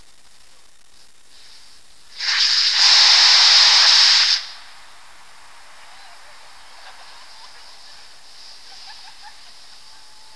big KNO3/icing sugar engine test
About 12lbs of fuel were burnt in 2.2 seconds!
burn time: 2.2 seconds
rockettest1.wav